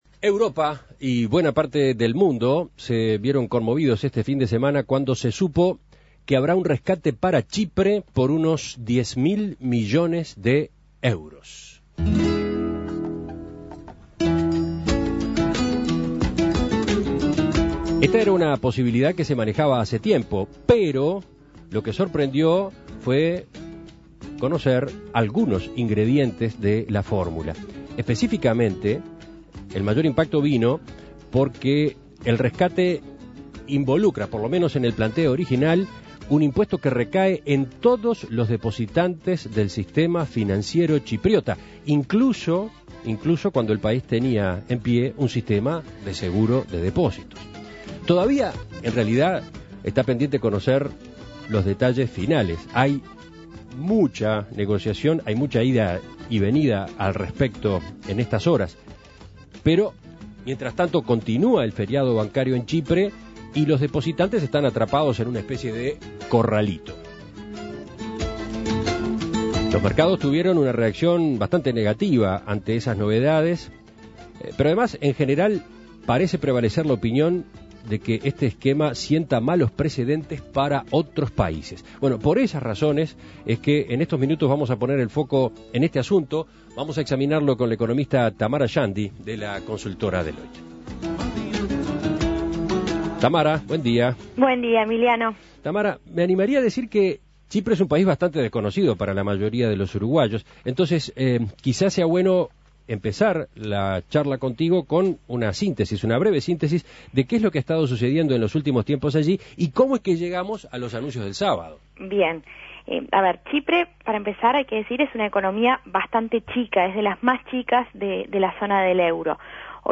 Análisis Económico El rescate de Chipre y las implicancias para el resto de las economías de la Eurozona